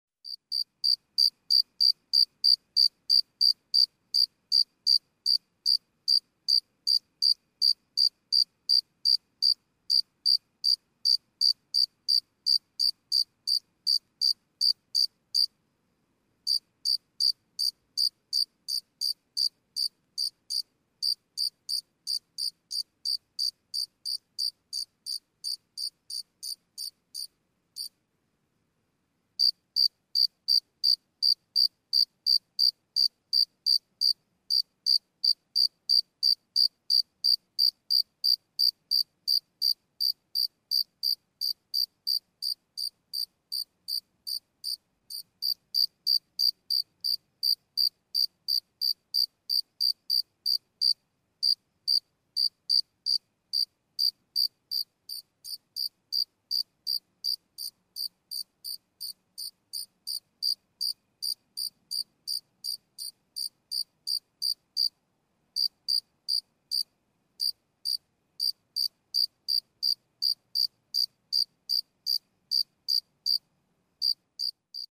Звуки стрекотания
Звук ночной мелодии